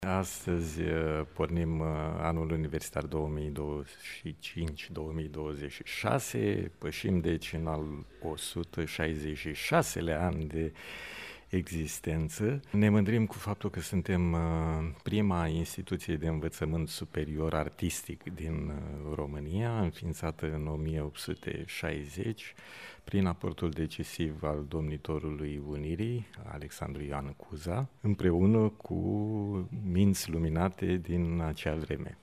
Universitatea Națională de Arte „George Enescu” din Iași a deschis, astăzi, Anul Academic. Ceremonia a avut loc în Sala „Caudella”, aflată în Casa Balș, sediul Rectoratului UNAGE.